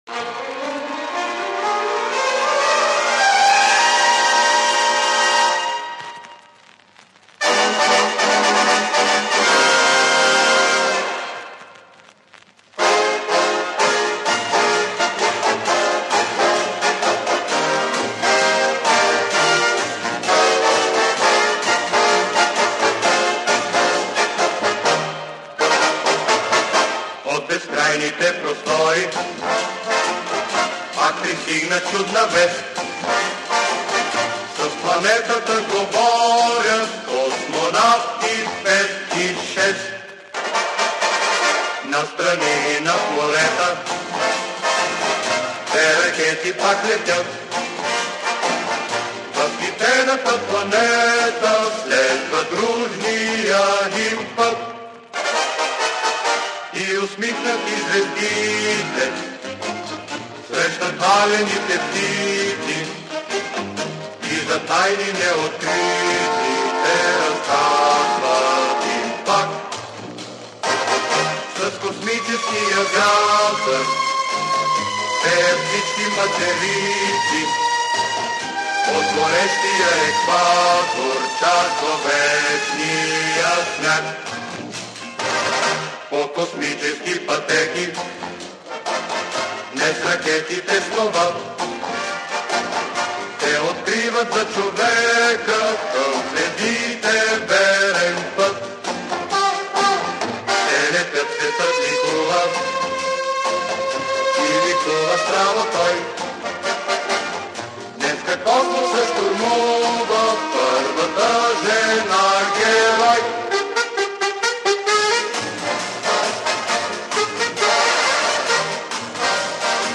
Болгарская песенка для Валентины Терешковой. 1963 год